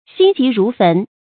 注音：ㄒㄧㄣ ㄐㄧˊ ㄖㄨˊ ㄈㄣˊ
心急如焚的讀法